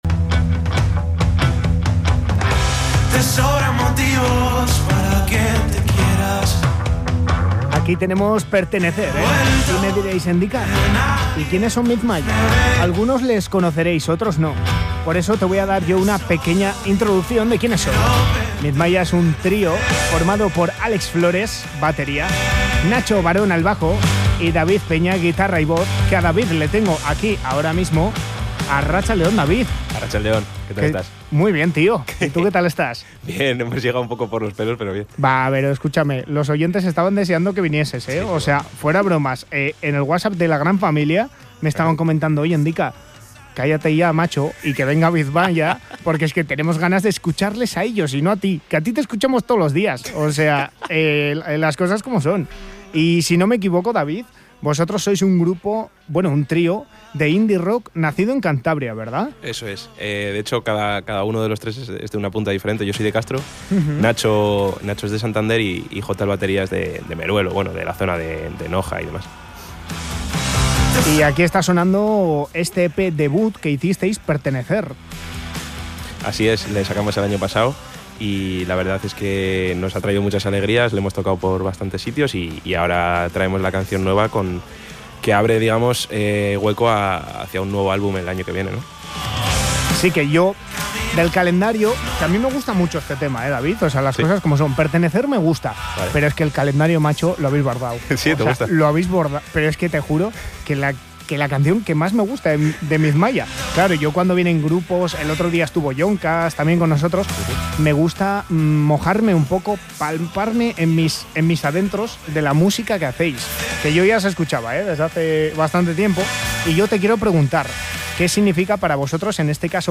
Entrevista a Mizmaya (12/06/2025)